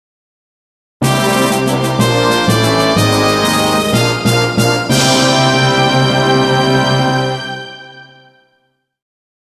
5．輝く勇者 ステージクリアジングル。↑と同様、こちらも王道的ファンファーレ。
04_stage_clear.mp3